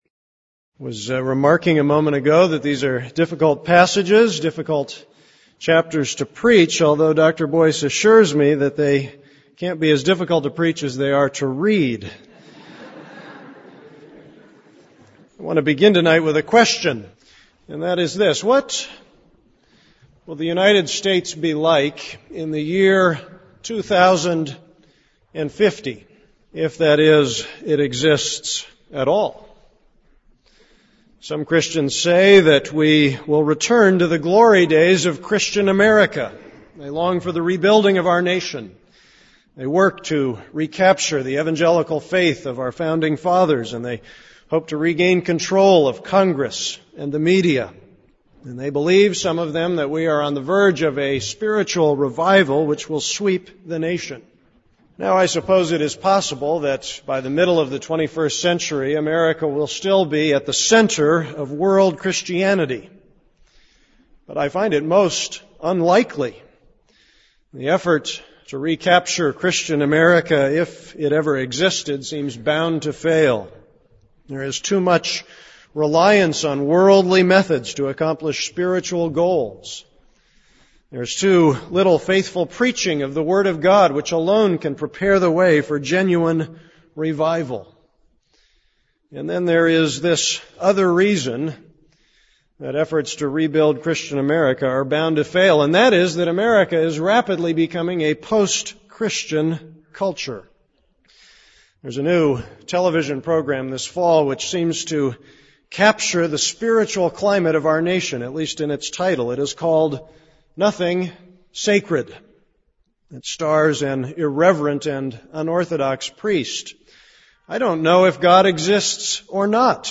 This is a sermon on Jeremiah 40:1-4.